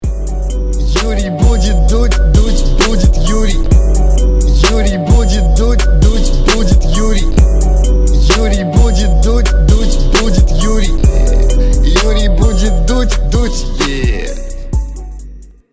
• Качество: 320, Stereo
Веселая заставка шоу на YouTube